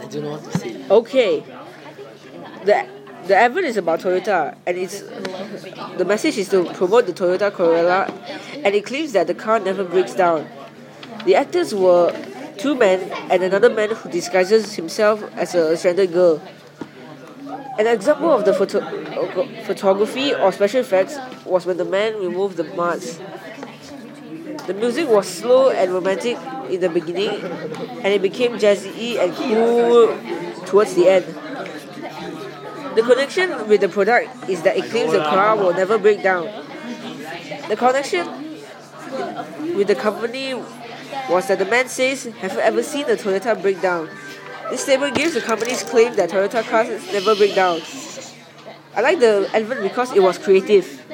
Toyota corolla ad